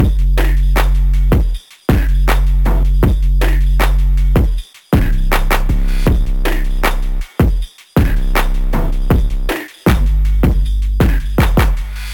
Dirty Phonk Saturated Drum Loop Gm 158.ogg
Hard punchy kick sample for Memphis Phonk/ Hip Hop and Trap like sound.